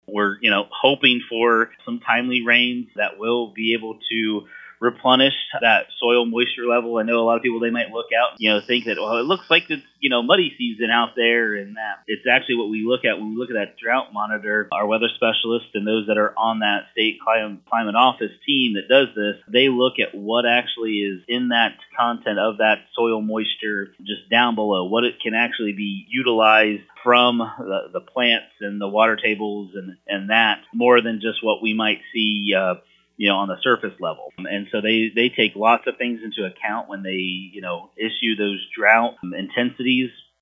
EDUCATOR